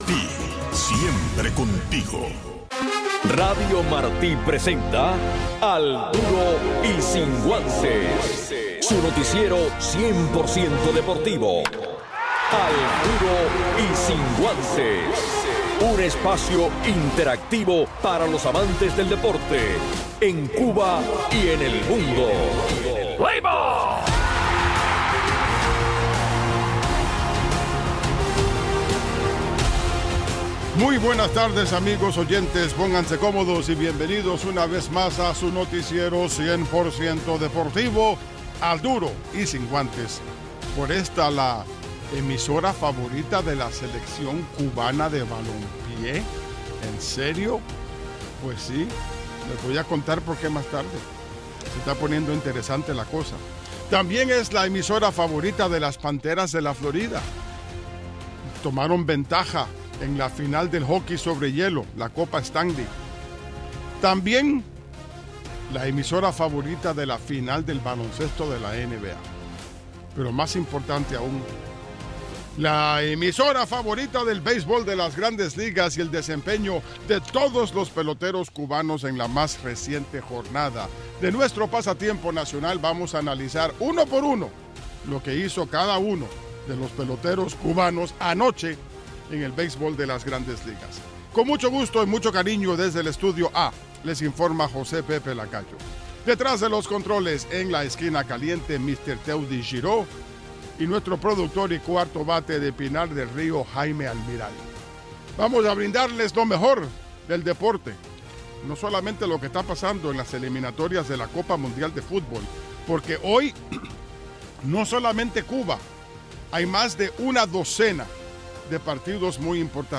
Un resumen deportivo en 60 minutos